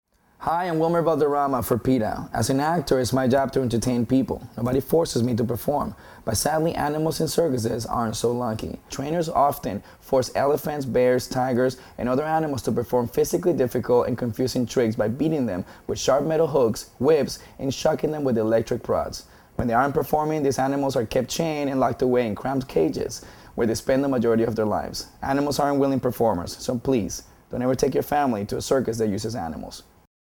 Instructions for Downloading This Radio PSA Audio File
wilmer_valderrama_anti-circus_radio_psa_peta_english.mp3